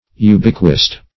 Search Result for " ubiquist" : The Collaborative International Dictionary of English v.0.48: Ubiquist \U"bi*quist\, Ubiquitarian \U*biq`ui*ta"ri*an\, n. [L. ubique everywhere: cf. F. ubiquiste, ubiquitaire.
ubiquist.mp3